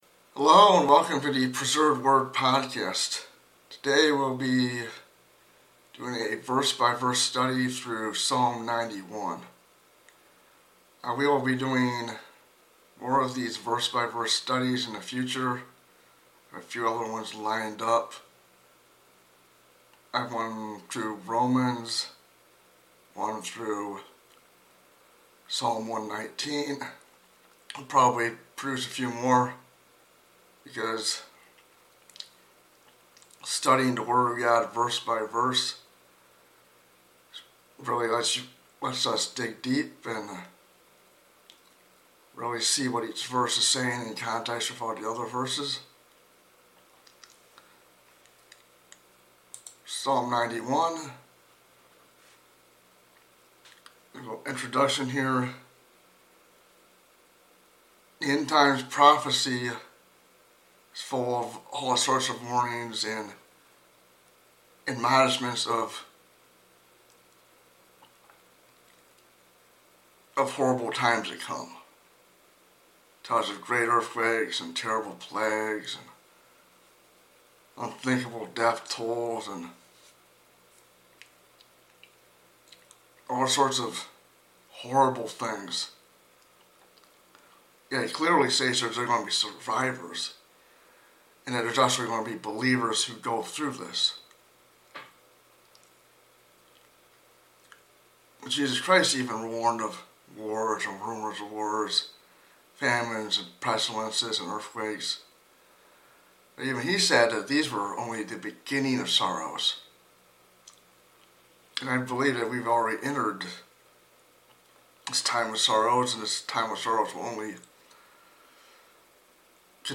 A verse-by-verse study of Psalm 91. This passage gives insight on how to survive coming disasters, including hinting at a genetically-engineered plague.